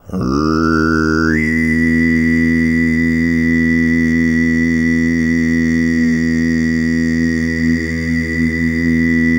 TUV3 DRONE09.wav